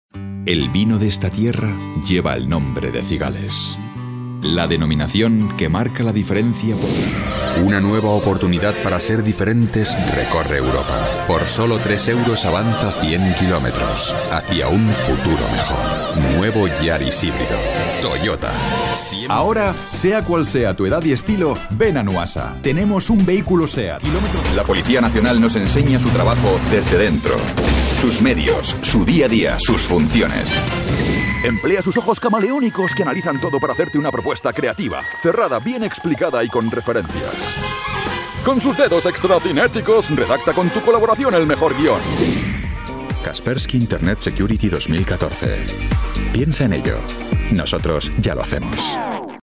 Versatile, Reliable, Friendly, Warm, Corporate
Commercial